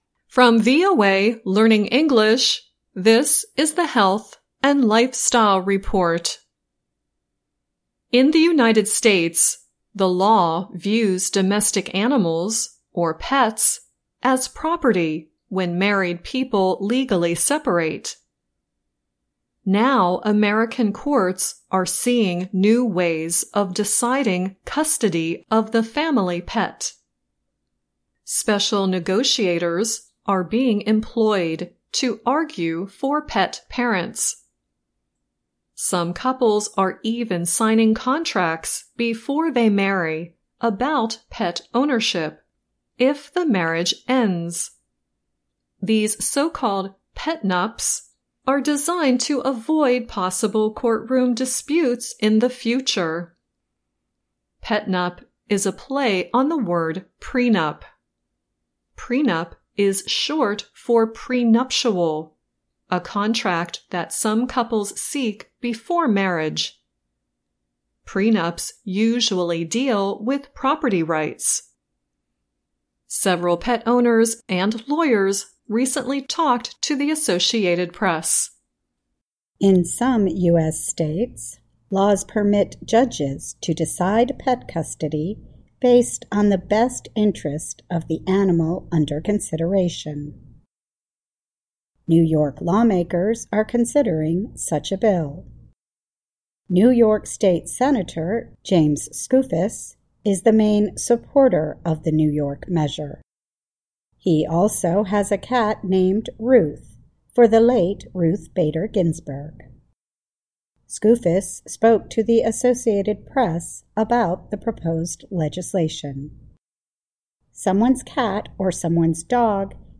Health & Lifestyle
by VOA - Voice of America English News